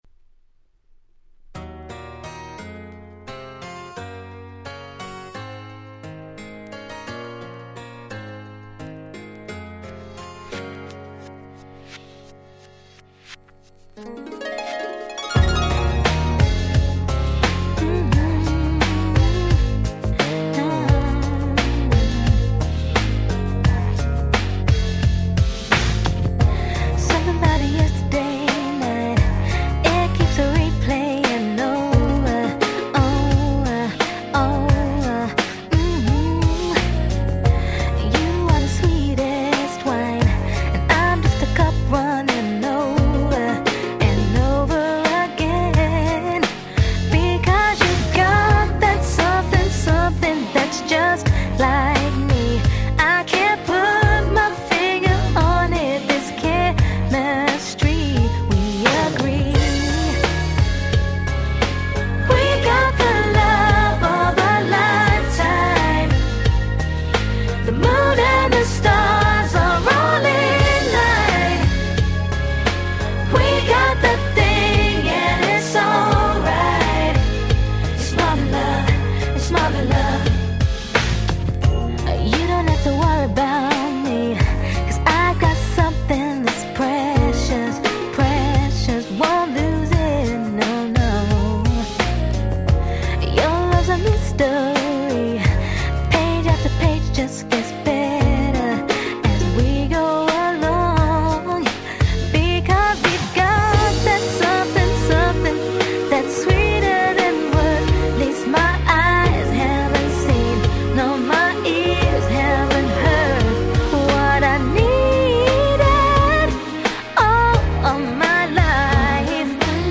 99' Nice UK R&B !!
UKの女の子3人組によるポップでキュートな1曲！